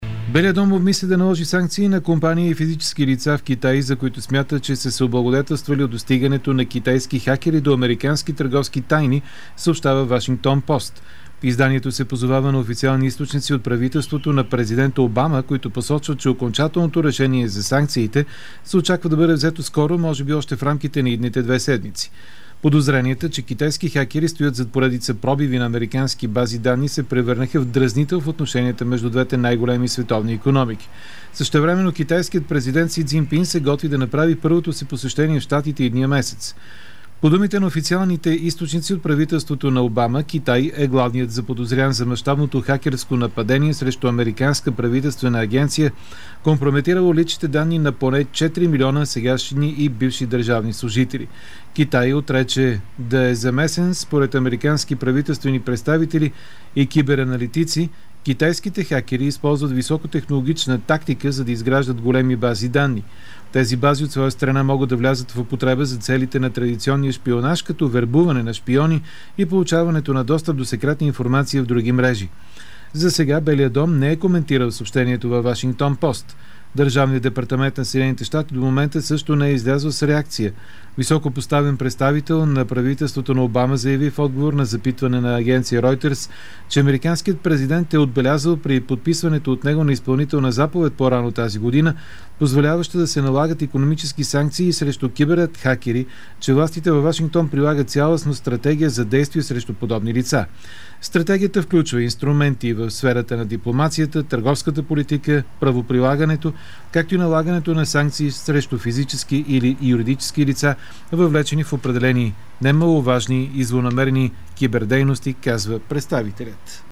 Новините в аудио изтегли Белият дом обмисля да наложи санкции на физически лица и компании в Китай, във връзка с мащабни хакерски атаки по американски правителствени агенции.
Novinite-v-audio~101.mp3